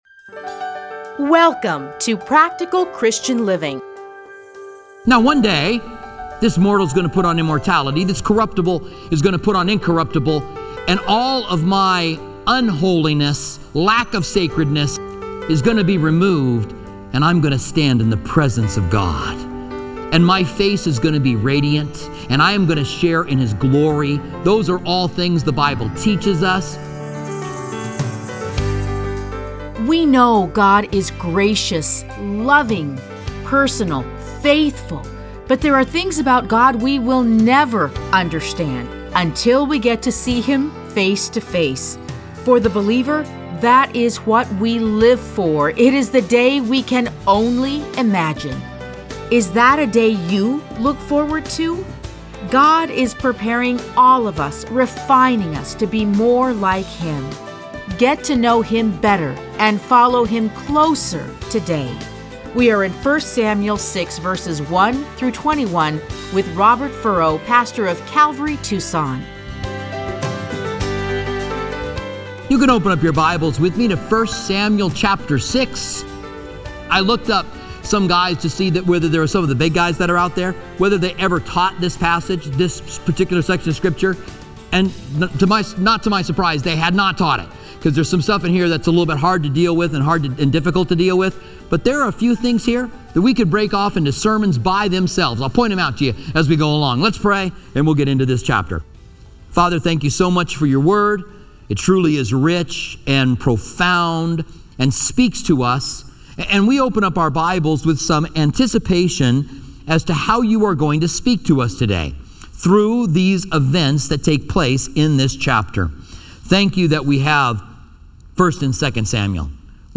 Listen to a teaching from 1 Samuel 6:1-21.